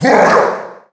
Below lies a collection of voice clips and sound effects from the first in the Mario Galaxy series!